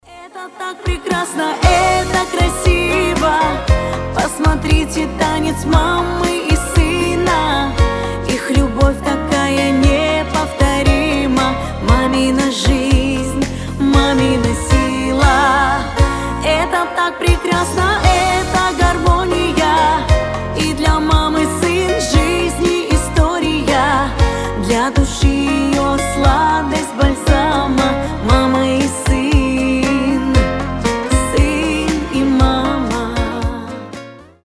гитара
душевные
медленные
добрые
свадебные